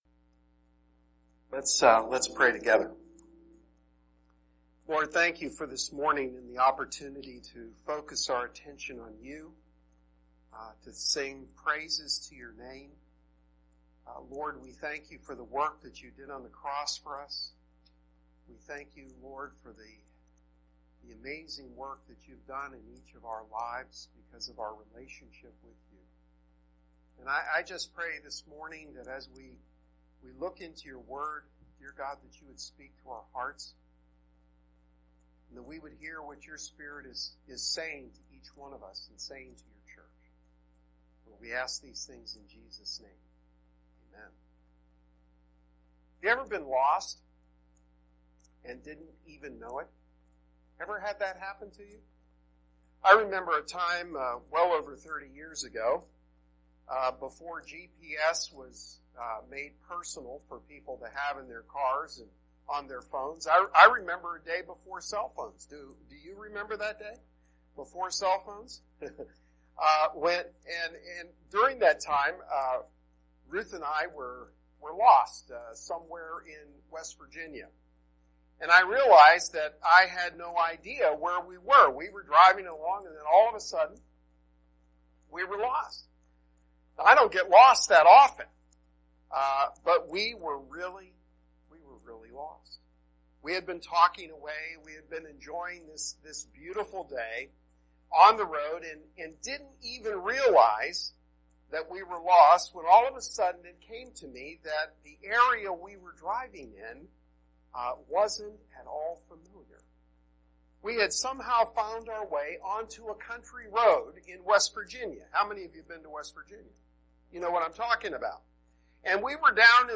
While we were able to determine why the sermon wasn’t recording the noise remains; we have attempted to remove it with software and hope you will be are encouraged by today’s message.
Sermons